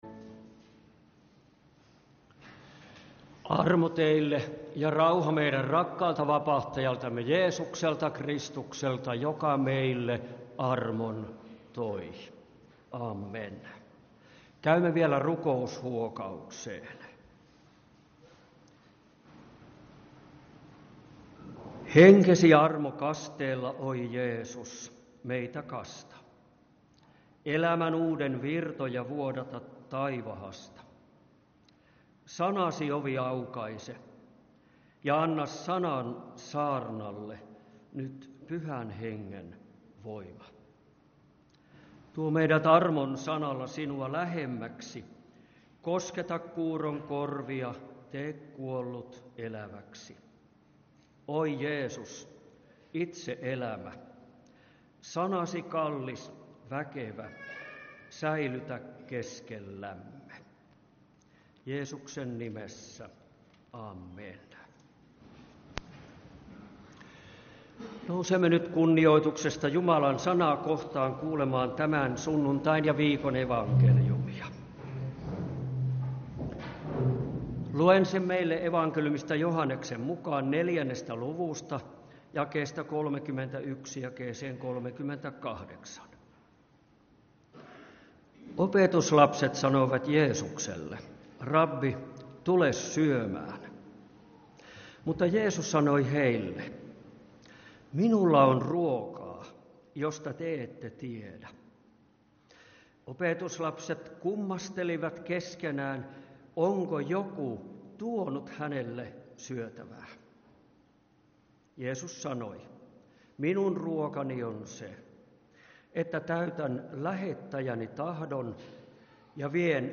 Kokoelmat: Lahden lutherin kirkon saarnat